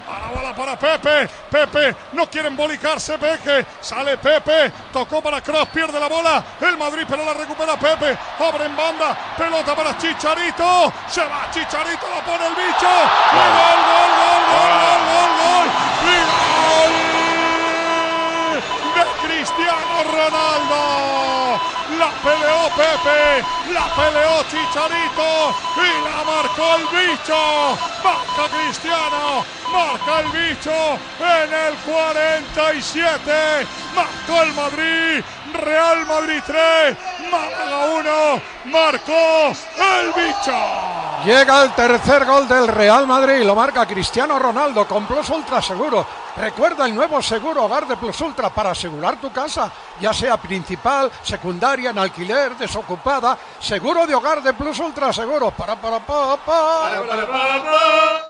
Transmissió del partit de la lliga de futbol masculí entre el Real Madrid i el Málaga.
Narració del gol de Cristiano Ronaldo i publicitat.
Esportiu